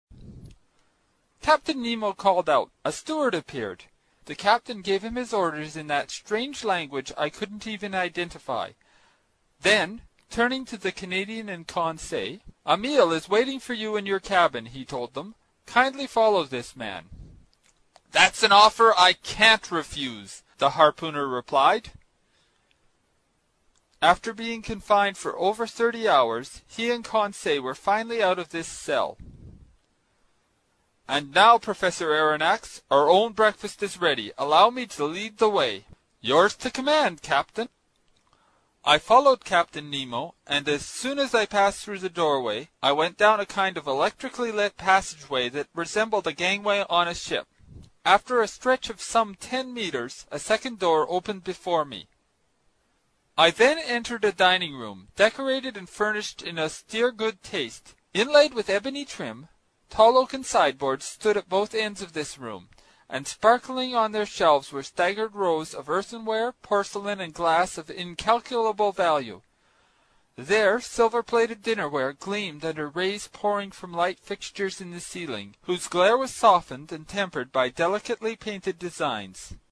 英语听书《海底两万里》第143期 第10章 水中人(14) 听力文件下载—在线英语听力室
在线英语听力室英语听书《海底两万里》第143期 第10章 水中人(14)的听力文件下载,《海底两万里》中英双语有声读物附MP3下载